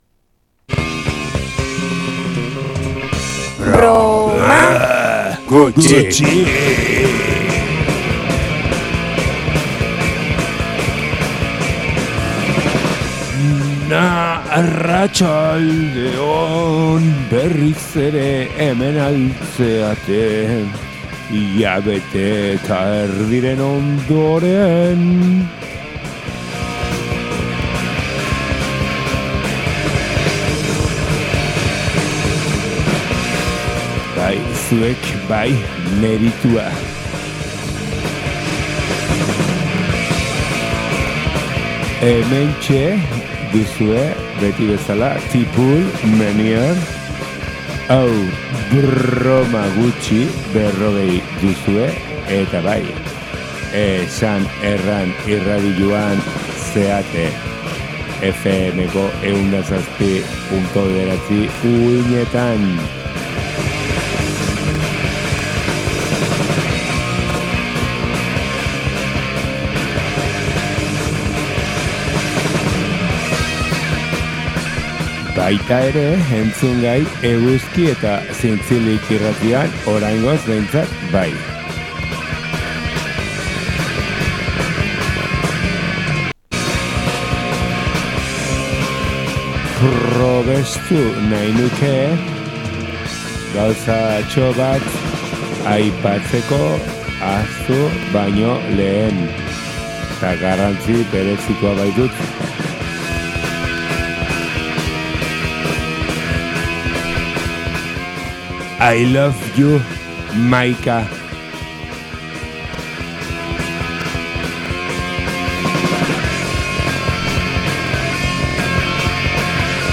Etxeko ganbaran hautsak hartuta zituen binilo zaharrak ekarriko dizkigu zuzenean jartzeko. Hardcore eta punk doinuak izanen dira nagusi irratsaioan, baina hausnarketarako eta ‘harrikadetarako’ tarterik ere izanen da. Zuzenekoa, ostegunetan 19:00etan.